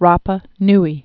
(räpə nē)